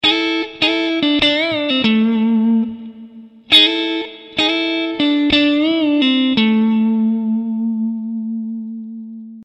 Fraseggio blues 04
La prima coppia di note viene suonata in slide anticipandone con un acciaccatura sul tasto precedente.